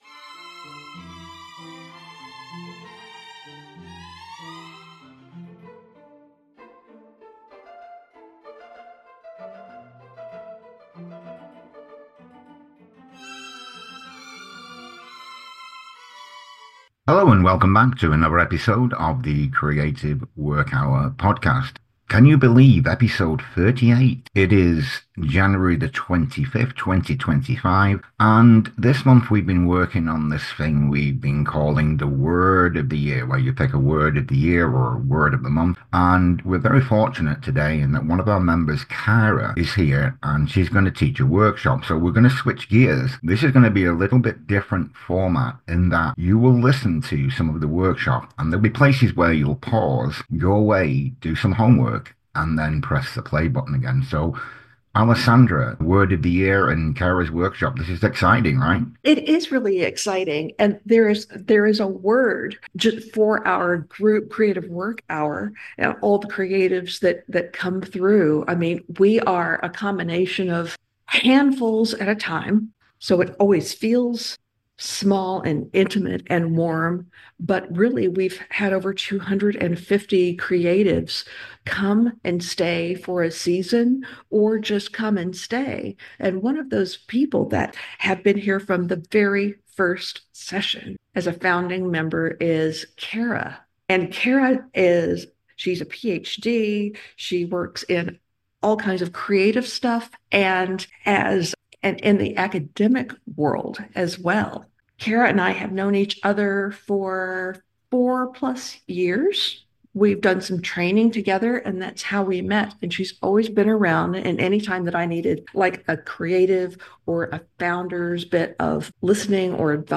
Our episodes feature in-depth conversations with participants and facilitators, sharing their unique insights and experiences in nurturing creativity.